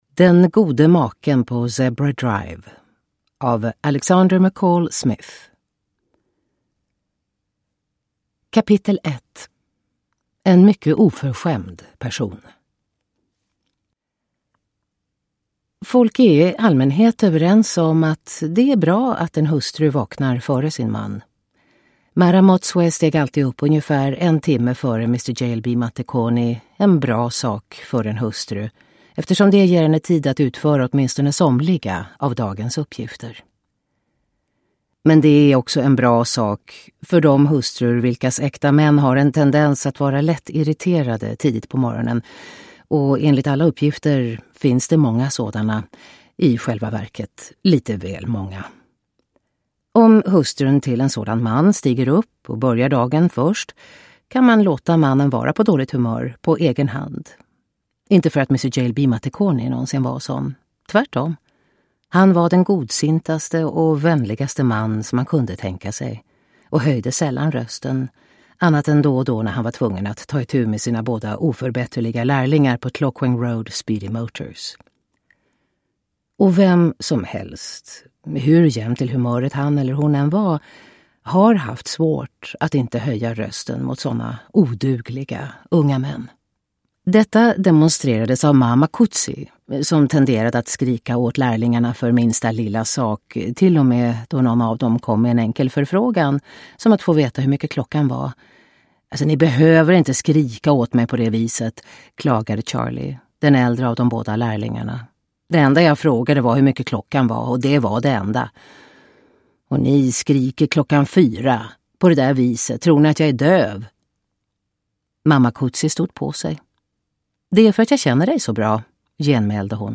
Den gode maken på Zebra Drive – Ljudbok – Laddas ner
Uppläsare: Katarina Ewerlöf